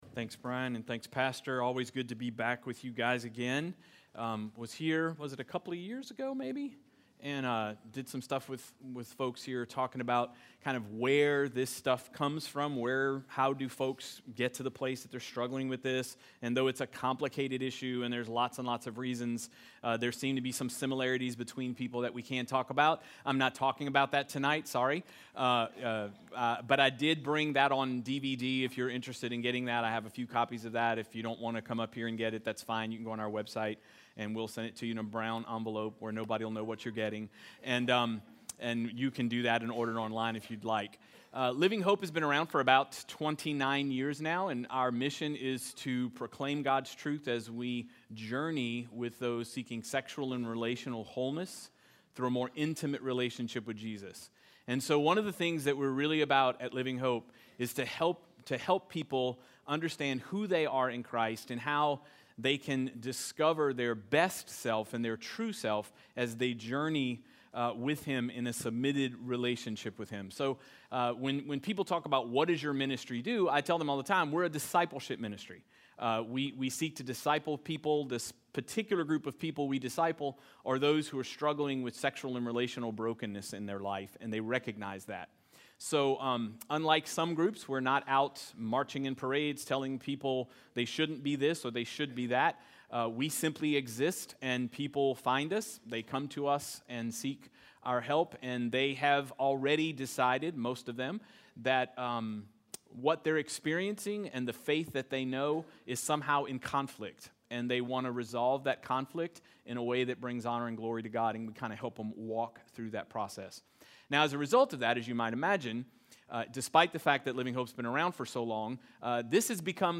WorldPassionWeek2018Conflict&CallingWednesdayEveningDiscussion.mp3